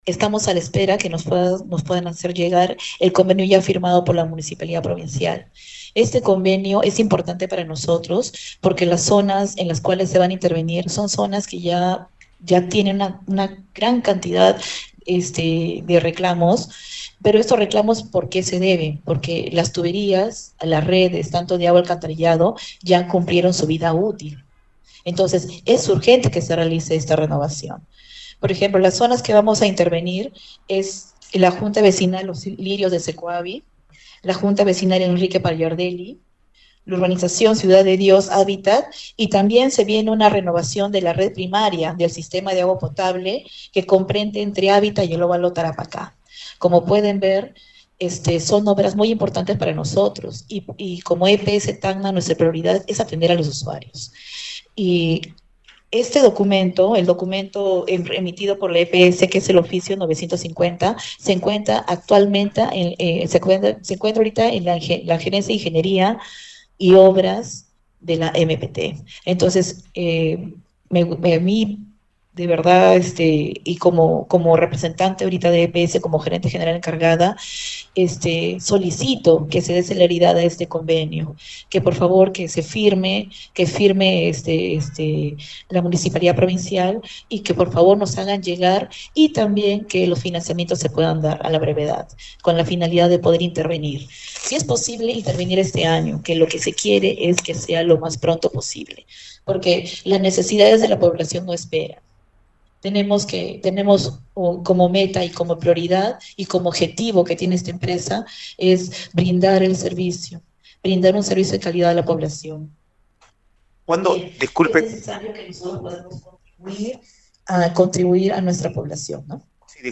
El directorio de la Empresa Prestadora de Servicios (EPS) – en conferencia este 5 de junio-  reveló que la gestión del alcalde provincial Pascual Güisa Bravo aún no firma  convenios que permitirán la renovación de alcantarillado, pese a que dicho documento con oficio n° 950 ya se encuentra en la Gerencia de Ingeniería y Obras de la Municipalidad Provincial de Tacna (MPT).
conferencia-de-la-EPS.mp3